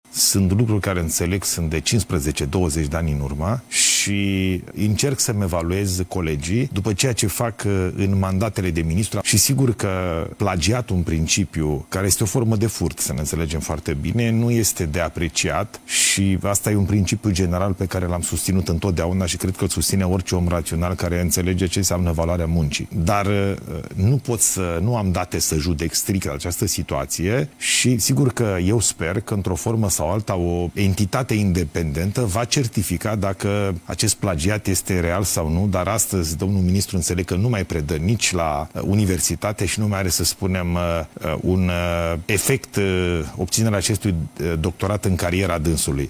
„Plagiatul este o formă de furt”, a mai spus premierul în interviu acordat televiziunii publice.